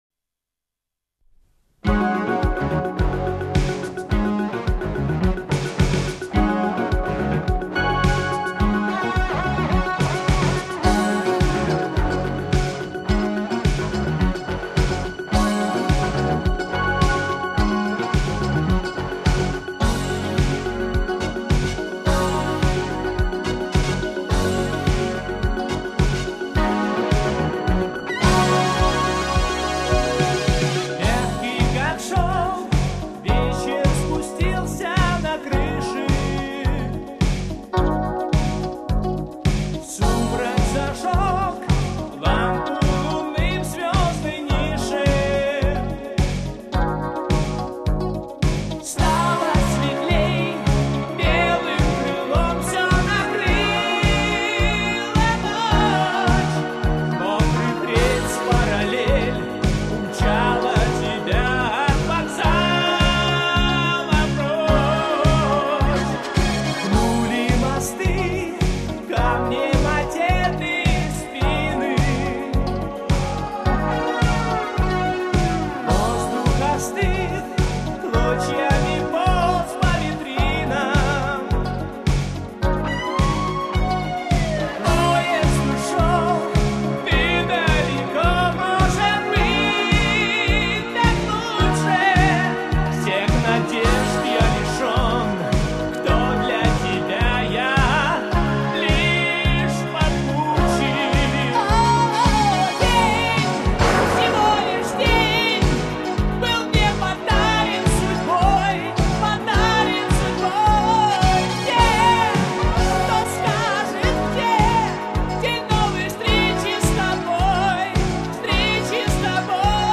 Вокал